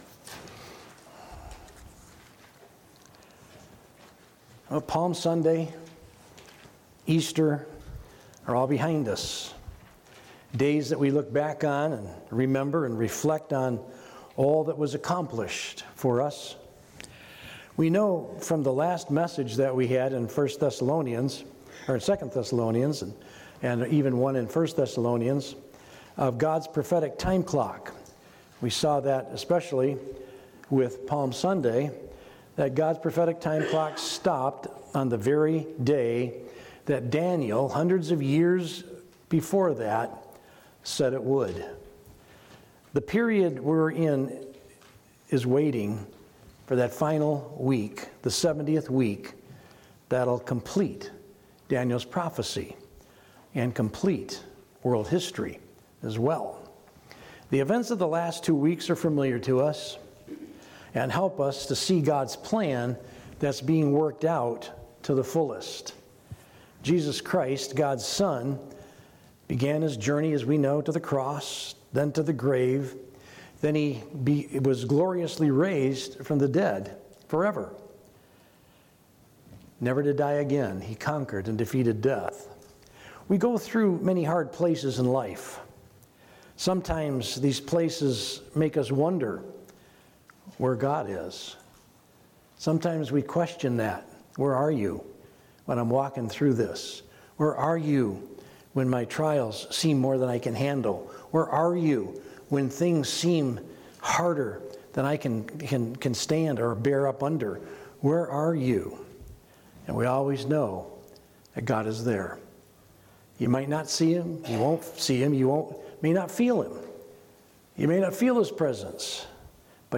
From Series: "Sunday Morning - 11:00"
Sermon